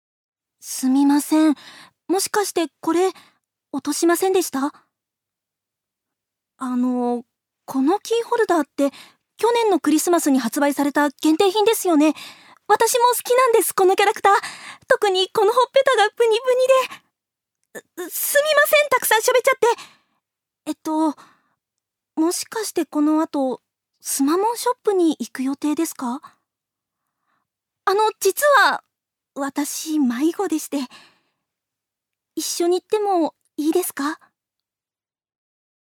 女性タレント
音声サンプル
セリフ２